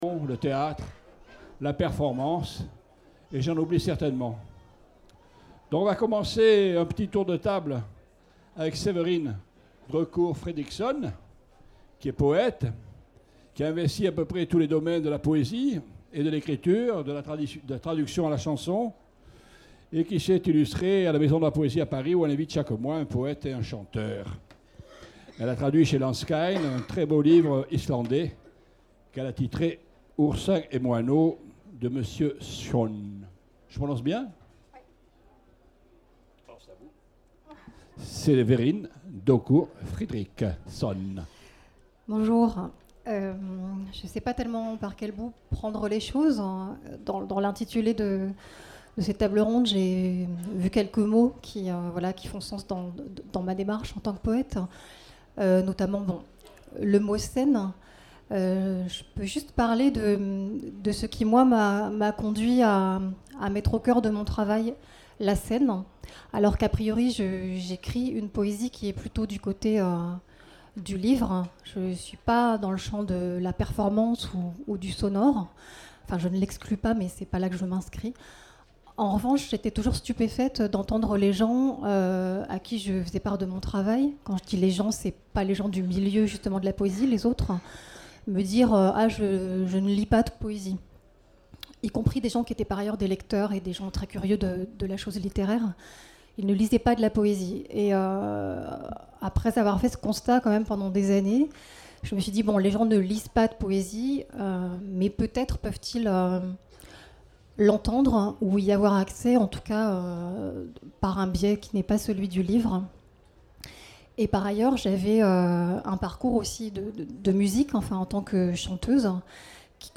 Intervention liminaire
discours d’ouverture